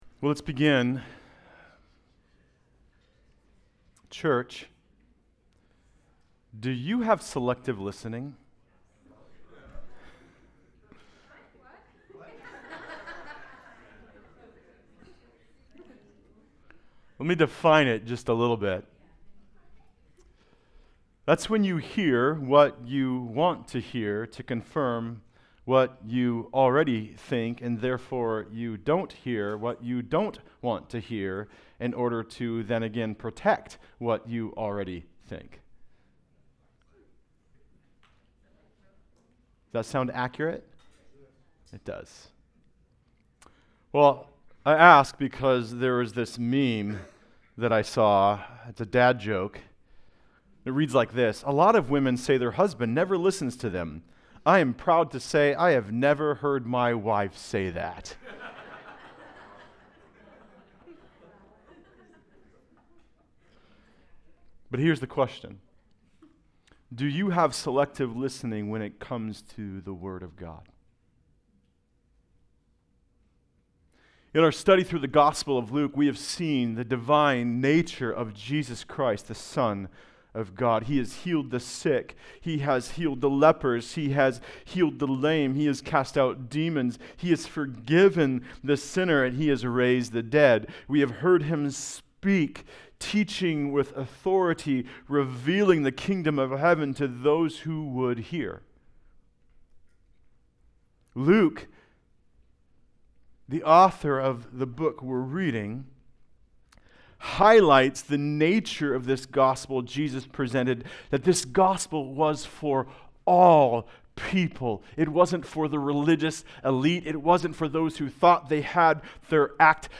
Good News for All People Passage: Luke 8:1-21 Service Type: Sunday Service Related « Forgiven Much Desperate Times